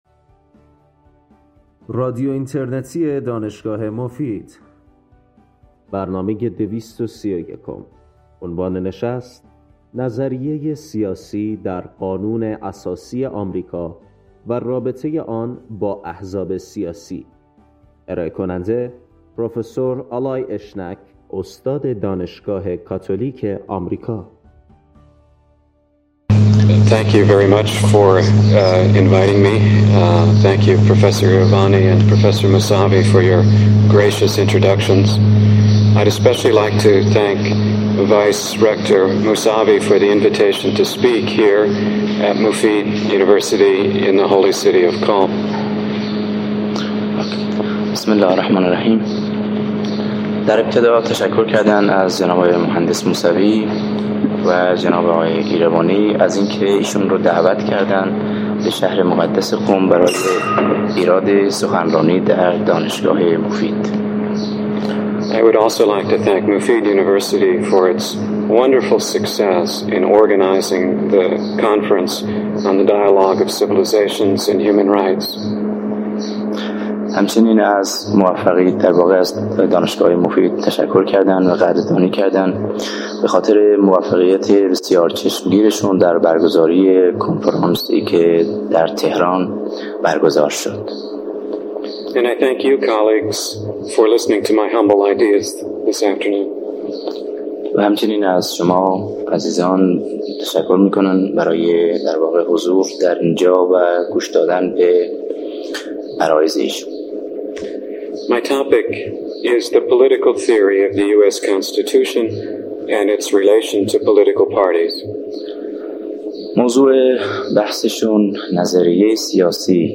این سخنرانی در سال ۱۳۸۰ و به زبان انگلیسی همراه با ترجمه فارسی ایراد شده است.